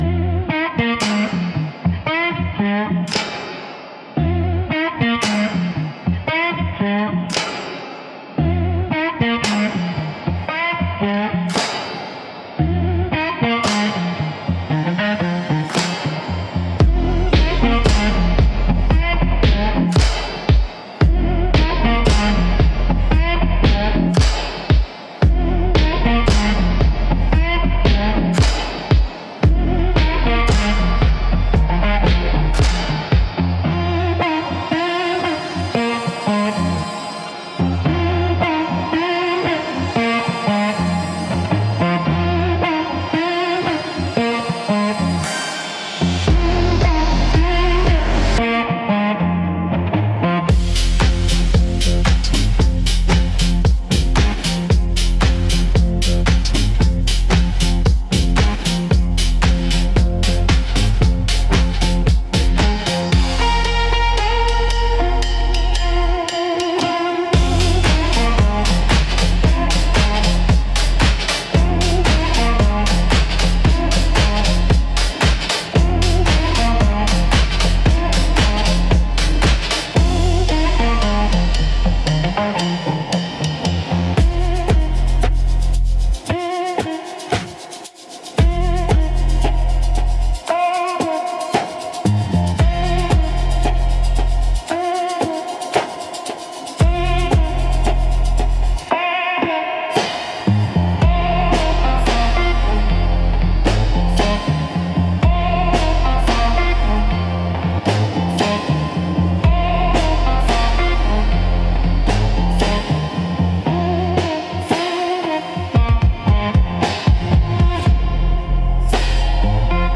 Instrumental - The Devil’s Wink